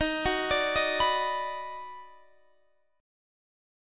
EbmM7#5 chord